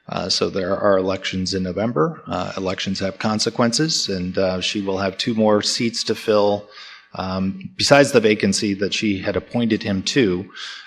at the Kalamazoo City Commission meeting May 2, 2022